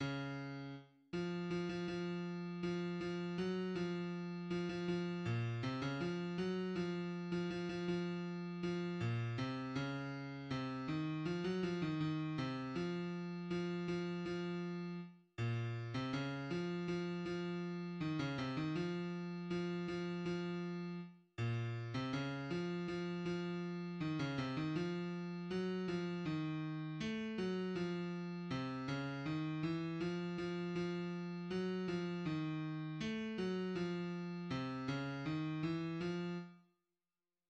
html5media>bass